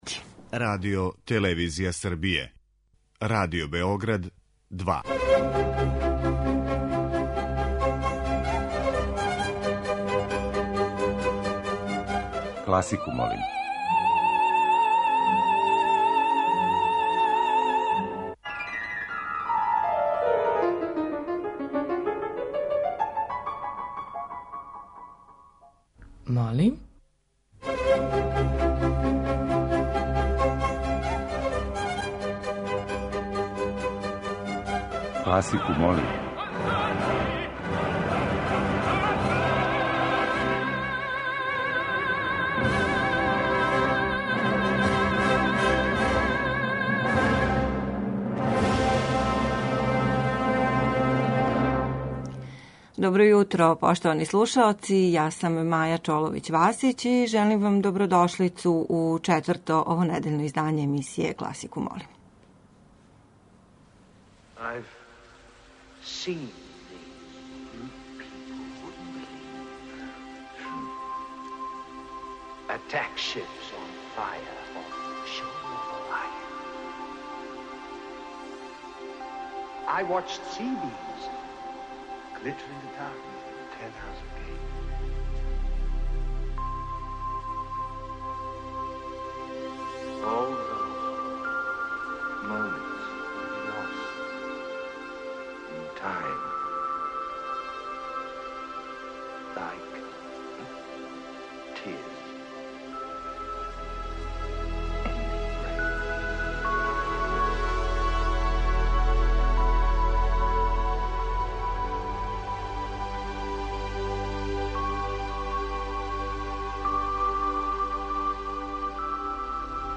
Као и обично, листу предлога за овонедељни хит класичне музике чине, како нови, тако и архивски снимци забележени на дискографским издањима и концертима реномираних домаћих и страних уметника. У оквиру теме циклуса чућете како су композитори попут Бацинија, Грига, Попера и Листа дочарали свет патуљака.
Уживо вођена емисија, окренута широком кругу љубитеља музике, разноврсног је садржаја, који се огледа у подједнакој заступљености свих музичких стилова, епоха и жанрова.